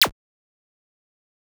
8bit_FX_Shot_01_01.wav